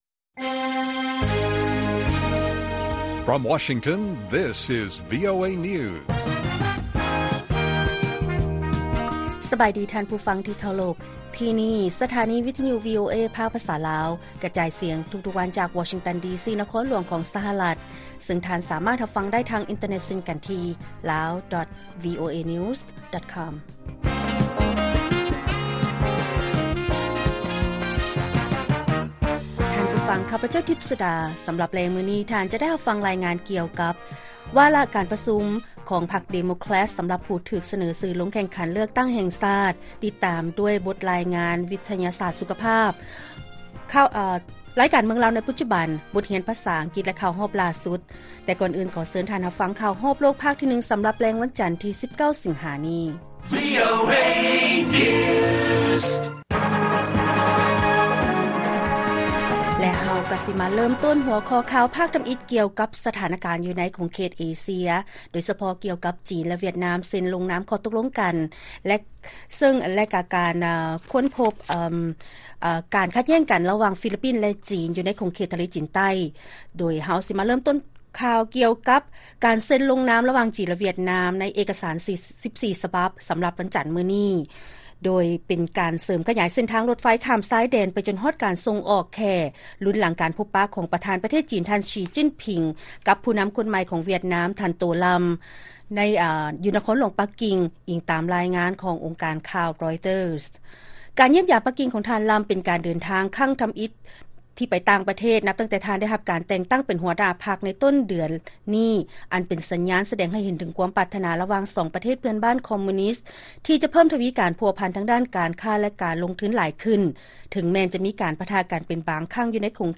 ລາຍການກະຈາຍສຽງຂອງວີໂອເອລາວ: ຈີນ ແລະ ຫວຽດນາມ ເຊັນລົງນາມເອກະສານ 14 ສະບັບ ນັບແຕ່ເສັ້ນທາງລົດໄຟ ຈົນຮອດການສົ່ງອອກແຂ້